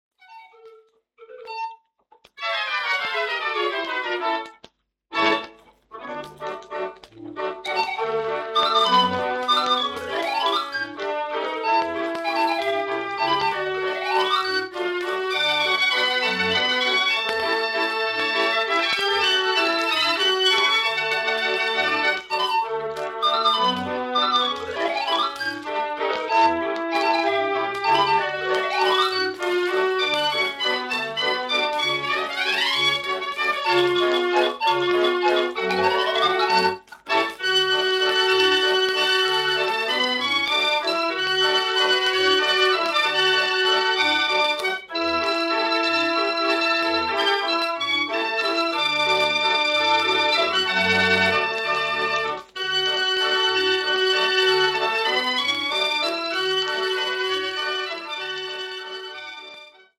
walsopnamen
als wals.
lichte en populaire repertoire dat op dit soort orgelplaten
Formaat 78 toerenplaat, 10 inch